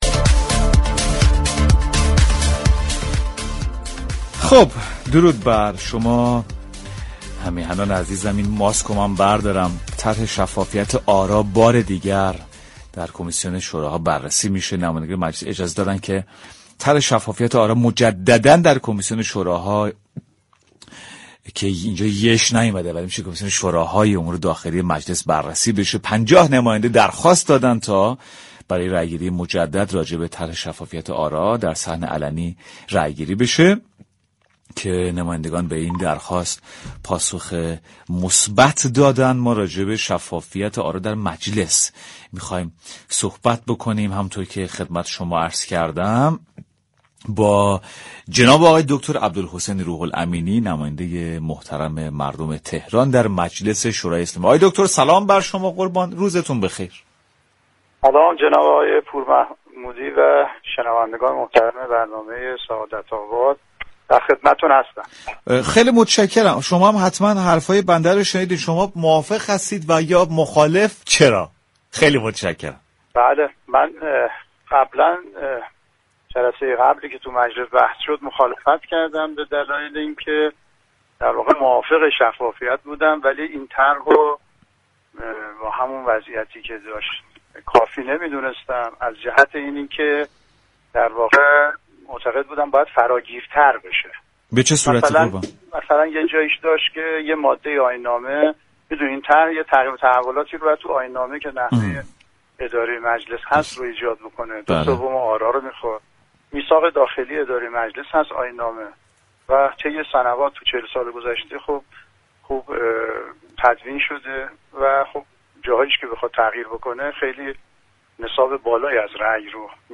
به گزارش پایگاه اطلاع رسانی رادیو تهران، عبدالحسین روح الامینی در گفتگو با برنامه سعادت آباد با اشاره به اینكه با طرح شفافیت آراء نمایندگان مجلس موافق است اظهار داشت: در جلسه قبل با این طرح مخالفت كردم نه به این خاطر كه با كلیات طرح مخالف هستم بلكه به این دلیل كه این طرح باید فراگیرتر می شد.